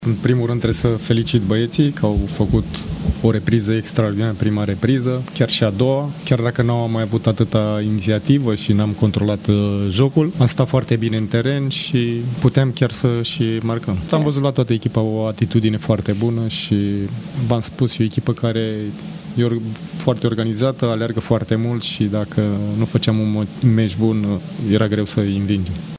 Antrenorul Costel Gâlcă își felicită jucătorii pentru atitudine, pentru efort și pentru prima repriză excelentă reușită în compania UTA-ei: